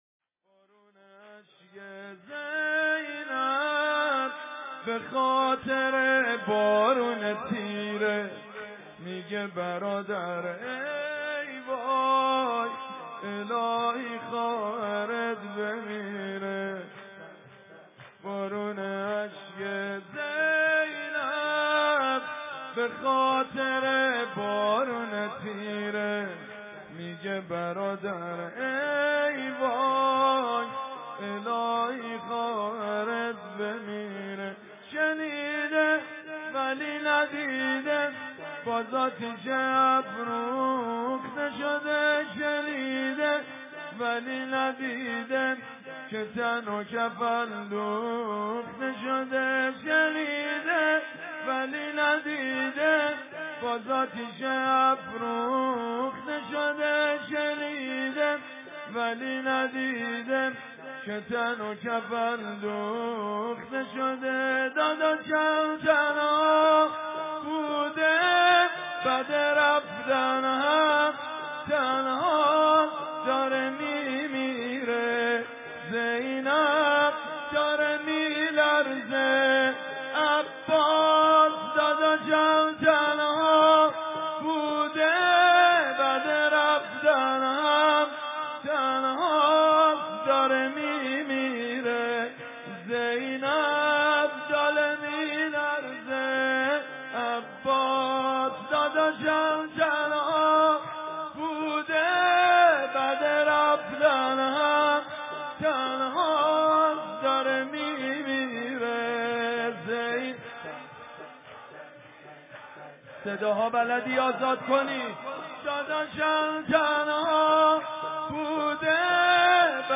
مداحی اربعین